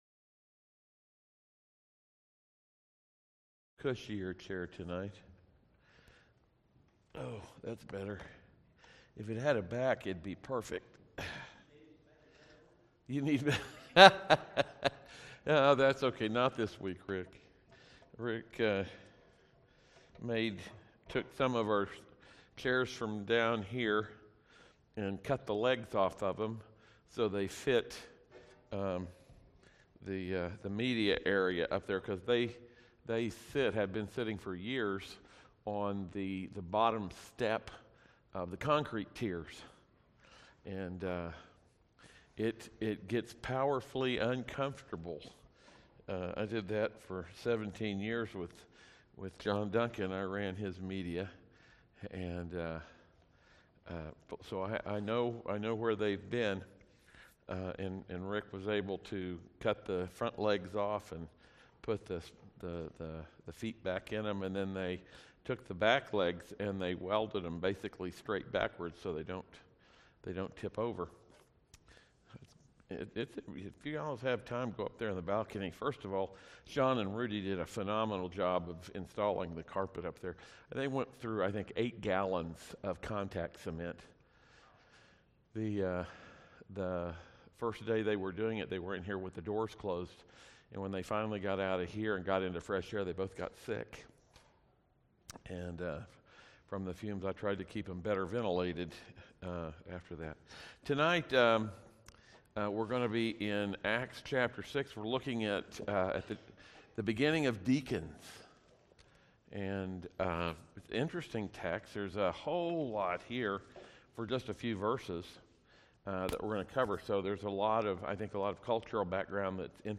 Service Type: audio sermons